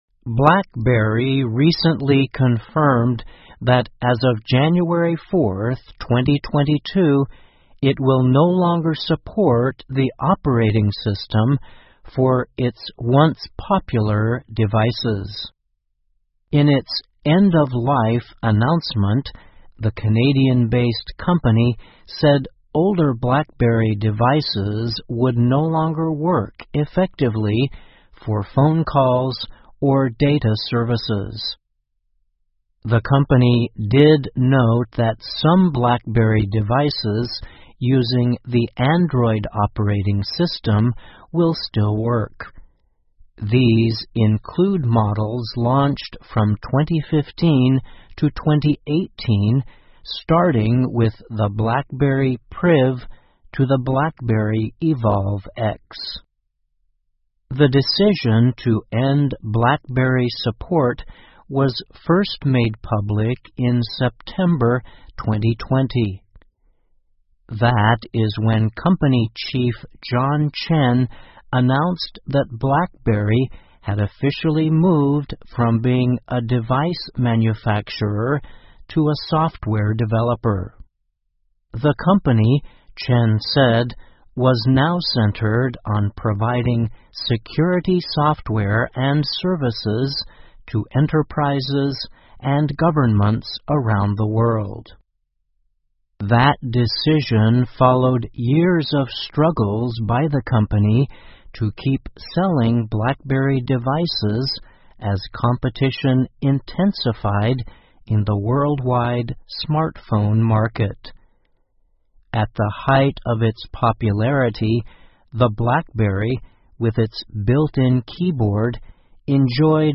VOA慢速英语2022--黑莓手机服务正式停运 听力文件下载—在线英语听力室